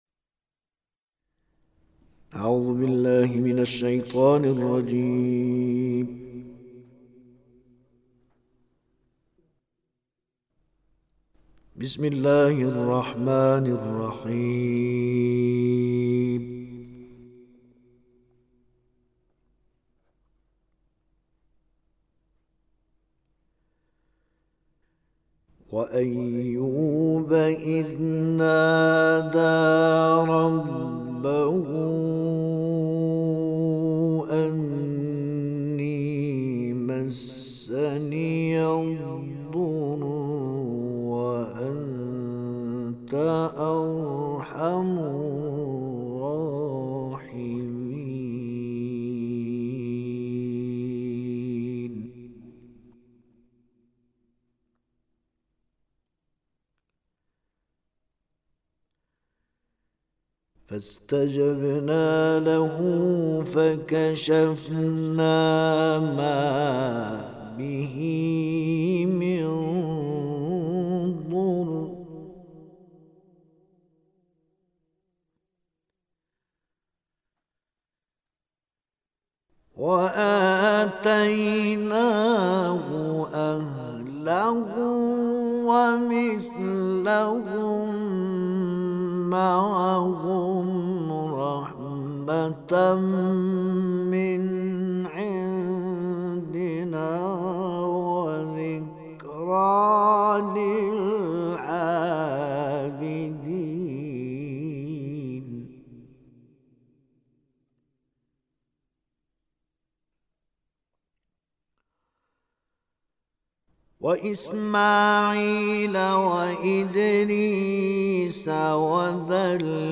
تلاوت استودیویی